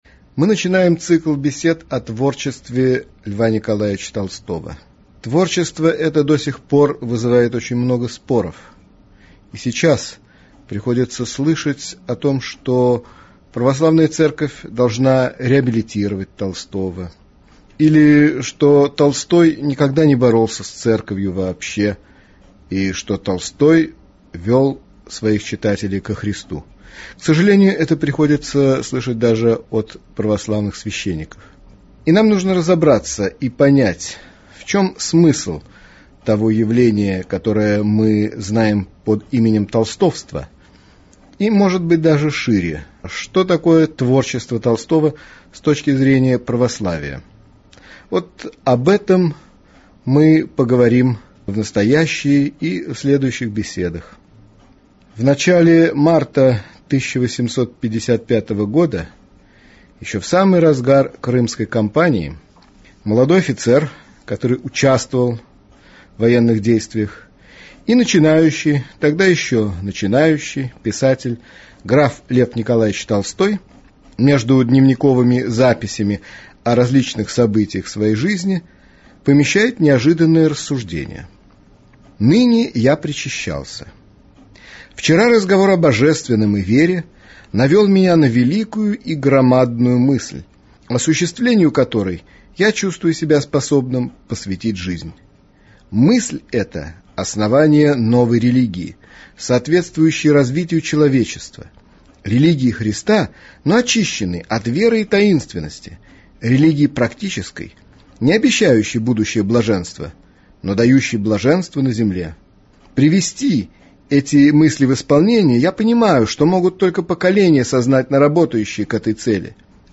Аудиокнига